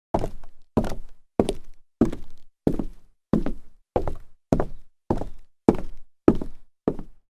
دانلود آهنگ کفش هنگام راه رفتن 1 از افکت صوتی انسان و موجودات زنده
جلوه های صوتی
دانلود صدای کفش هنگام راه رفتن 1 از ساعد نیوز با لینک مستقیم و کیفیت بالا